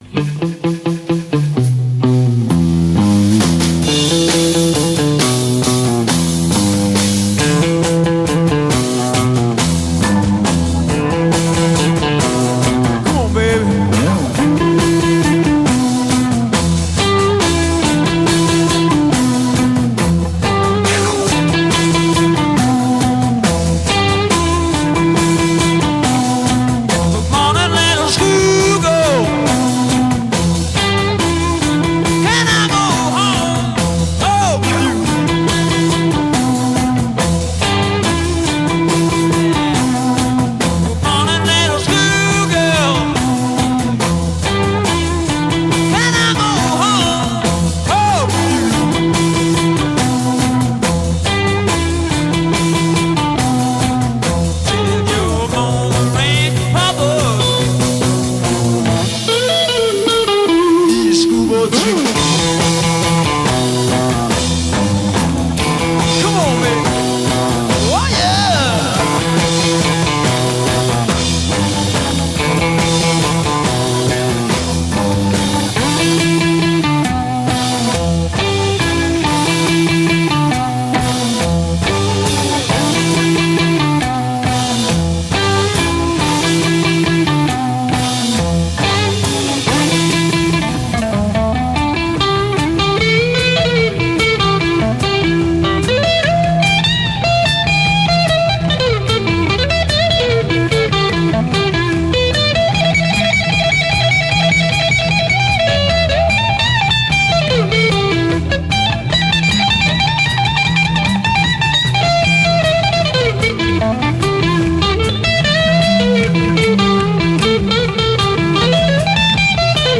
Blues Rock, Classic Rock